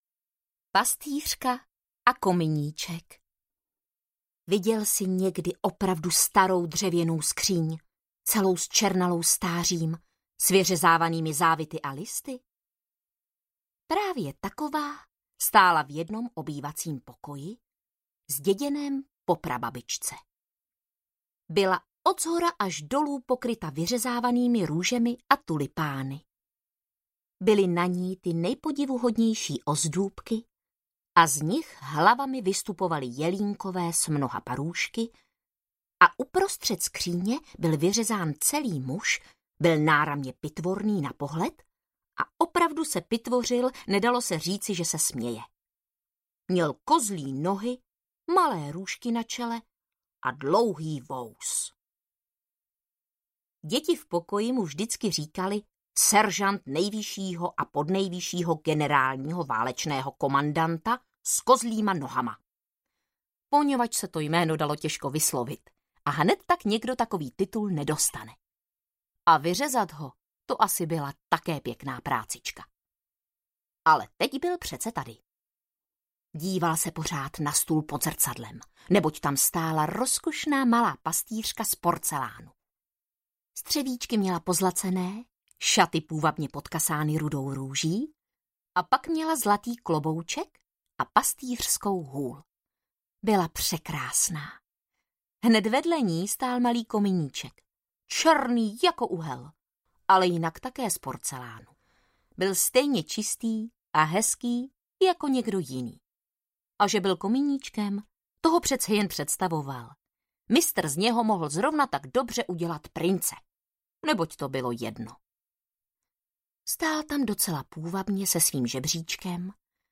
Pastýřka a kominíček audiokniha
Ukázka z knihy